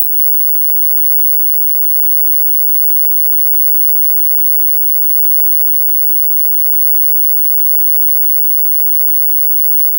flickering-beeping-sound--mqyu7pyf.wav